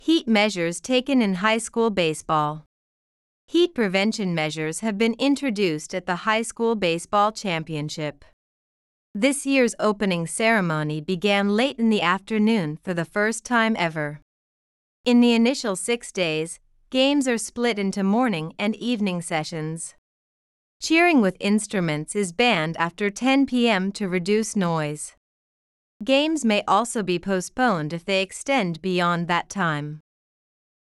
【スロースピード】